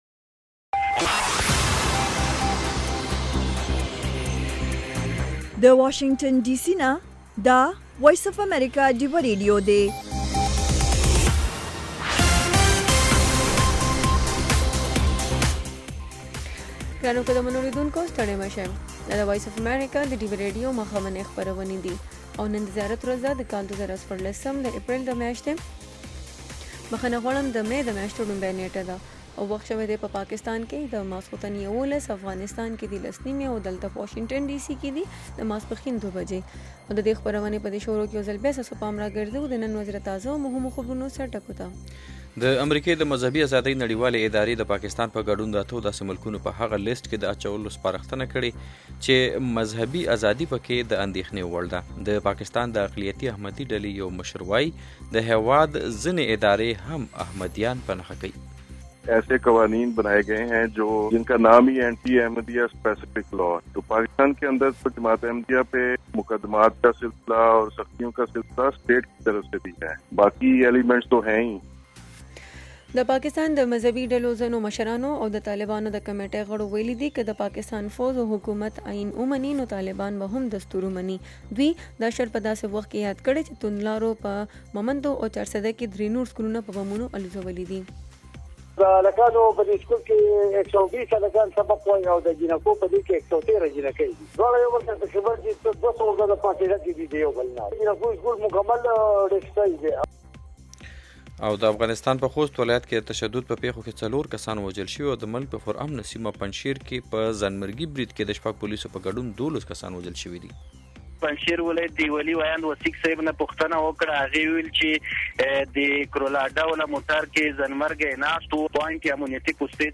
په دې خپرونه کې اوریدونکي کولی شي خپل شعر یا کوم پیغام نورو سره شریک کړي. د شپې ناوخته دا پروگرام د سټرو اوریدنکو لپاره ښائسته خبرې او سندرې هم لري.